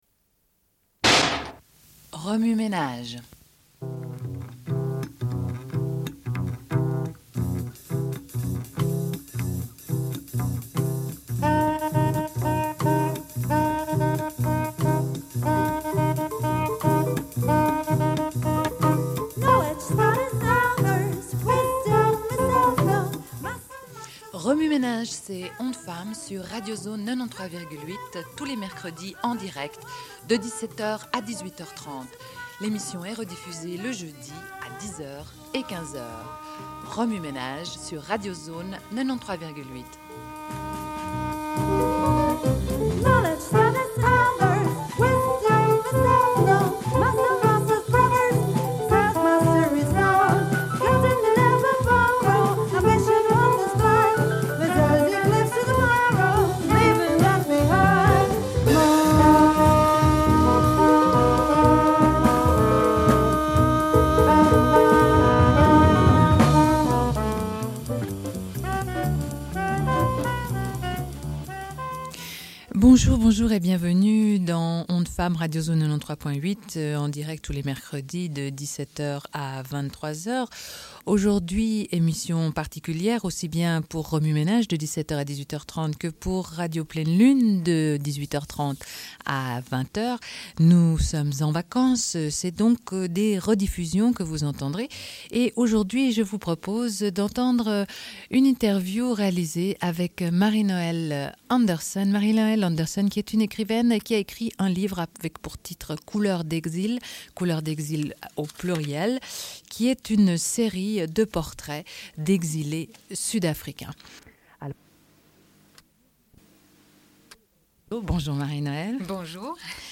Une cassette audio, face A31:03